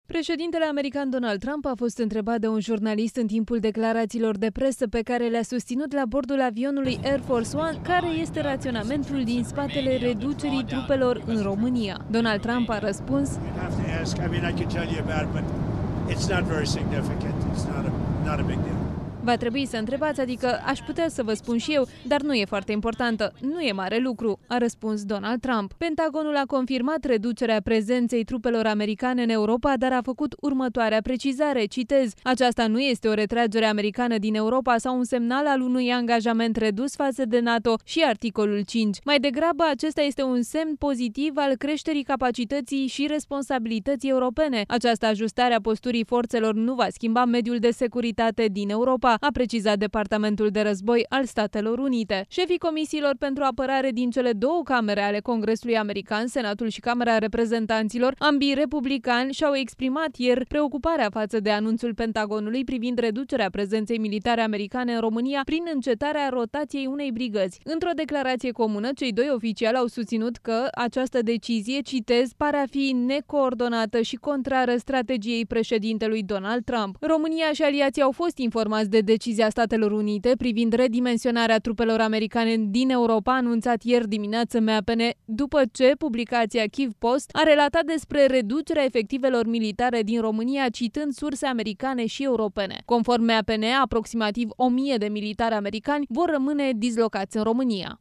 Preşedintele american Donald Trump a fost întrebat de un jurnalist în timpul declarațiilor de presă pe care le-a susținut la bordul avionului Air Force One: Care este raționamentul din spatele reducerii trupelor în România?